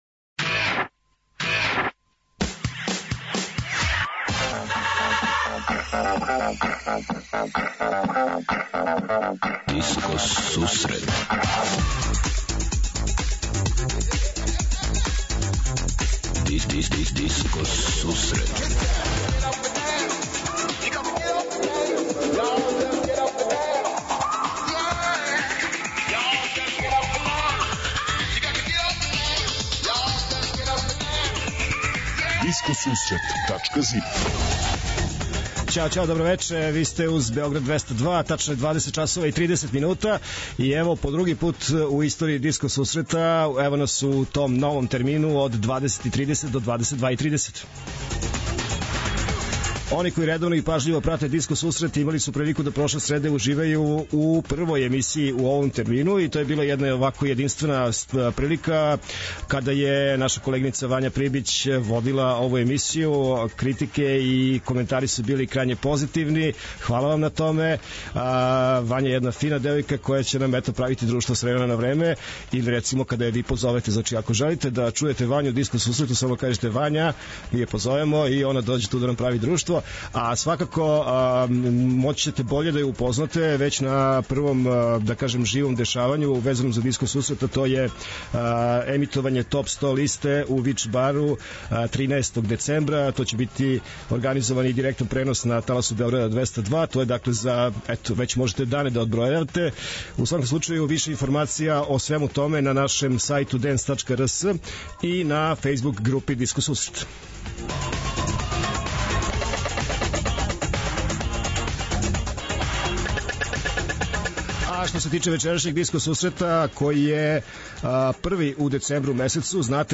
20:30 Диско Сусрет Топ 40 - Топ листа 40 актуелних синглова, пажљиво одабраних за оне који воле диско музику. 21:30 Винил Зона - Слушаоци, пријатељи и уредници Диско Сусрета пуштају музику са грамофонских плоча.
преузми : 28.44 MB Discoteca+ Autor: Београд 202 Discoteca+ је емисија посвећена најновијој и оригиналној диско музици у широком смислу, укључујући све стилске утицаје других музичких праваца - фанк, соул, РнБ, итало-диско, денс, поп.